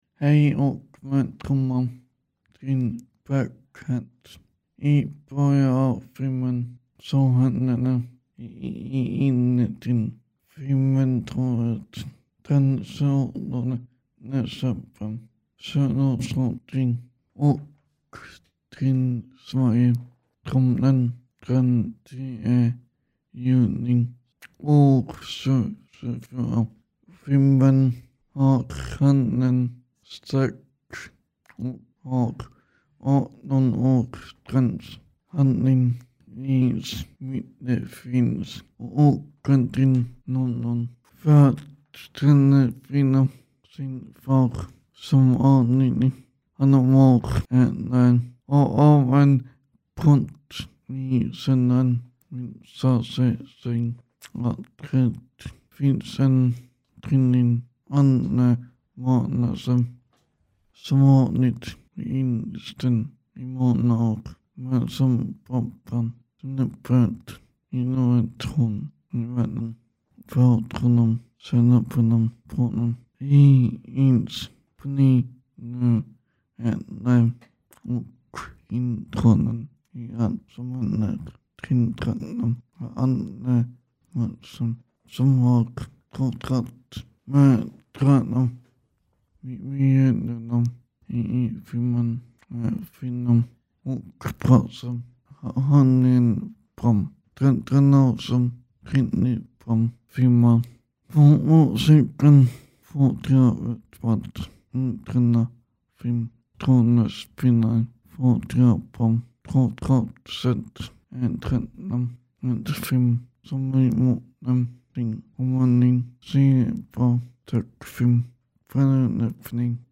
Baghead-recension-klippt.mp3